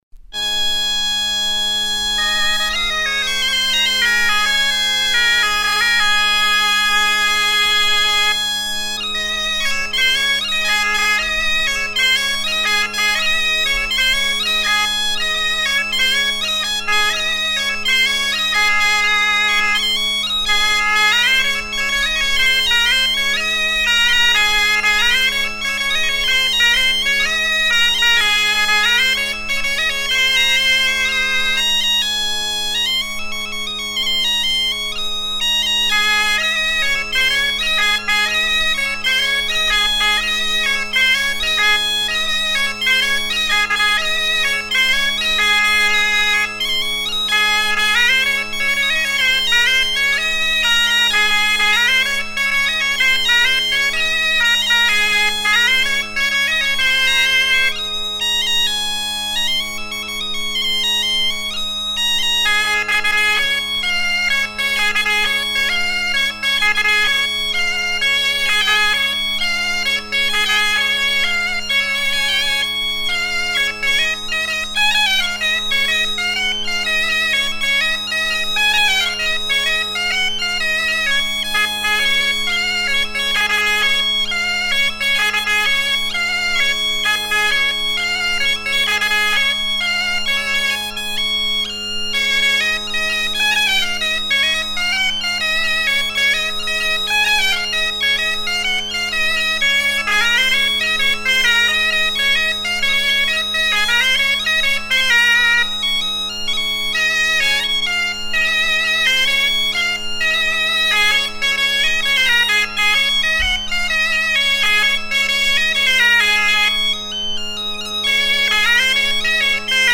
Trois airs vannetais soutenant le demi-tour, forme de onde à trois pas
danse : ronde : demi-tour